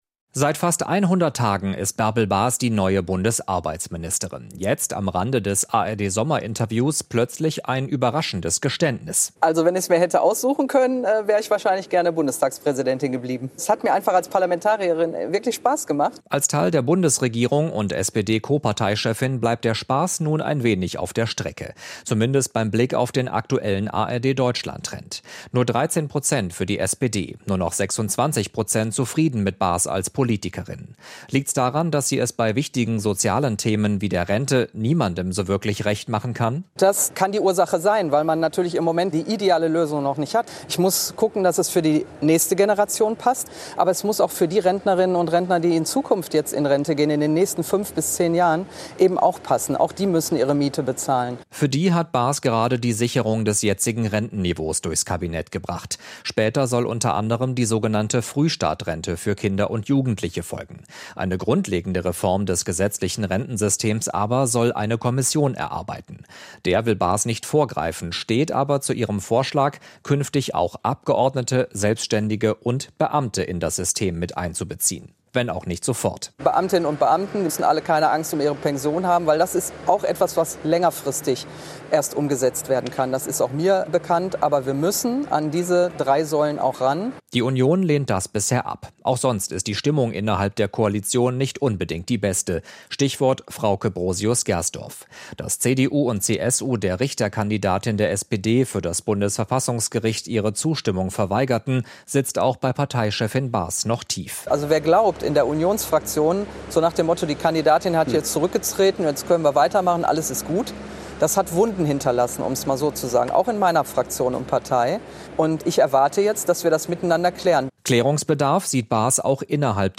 Bärbel Bas im Sommerinterview